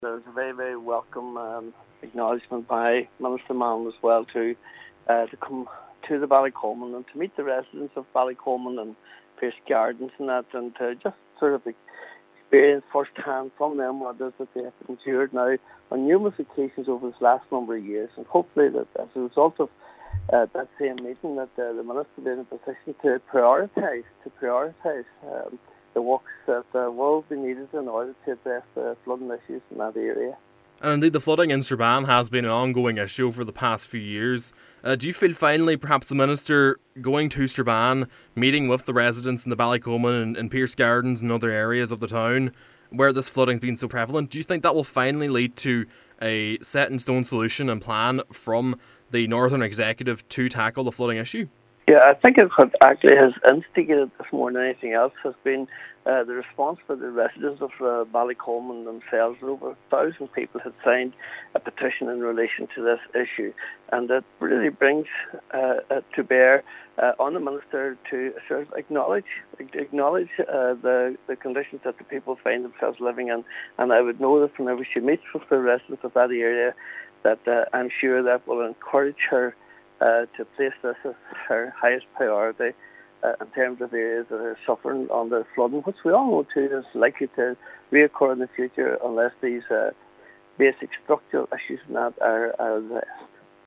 West Tyrone MLA Maolíosa McHugh is welcoming Minister Nichola Mallon’s commitment to visit the area and view first-hand the effects of flooding on the town: